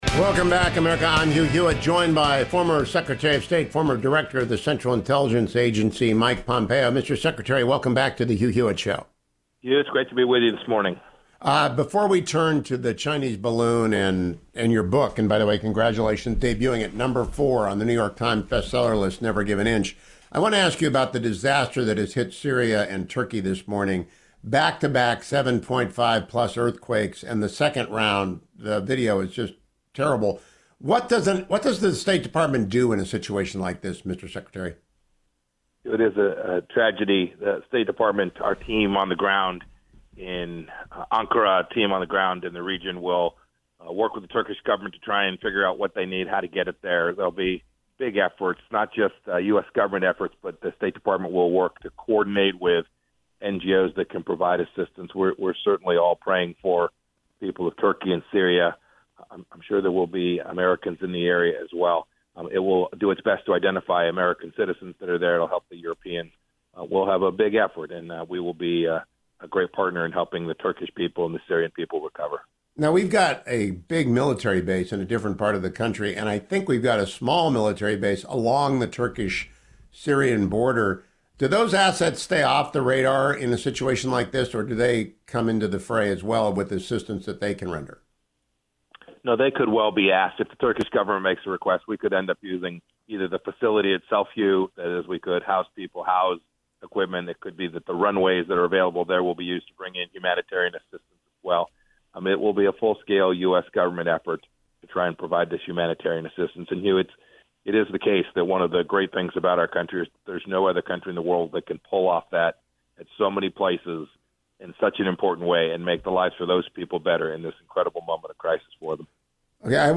Former Secretary of State Mike Pompeo joined me this morning to discuss the CCP’s spy balloon: